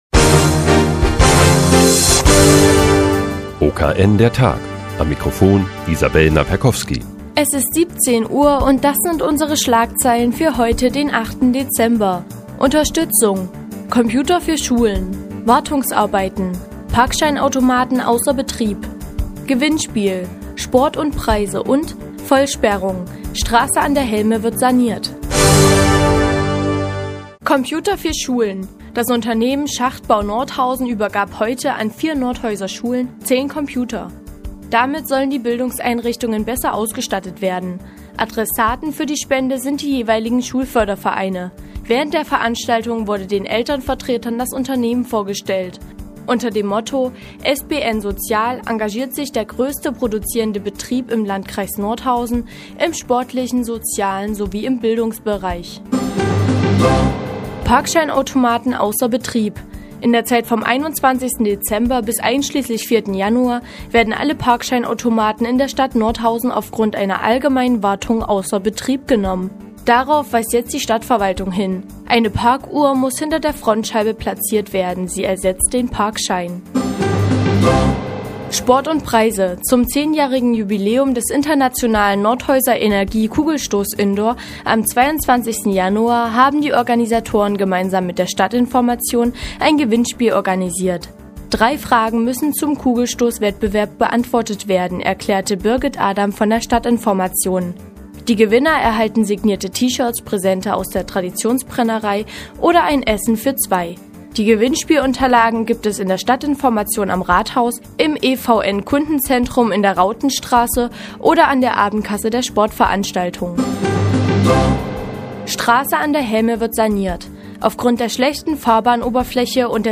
Die tägliche Nachrichtensendung des OKN ist nun auch in der nnz zu hören. Heute geht es um neue Computer für Nordhäuser Schulen und ein sportliches Gewinnspiel.